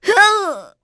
Glenwys-Vox_Damage_02.wav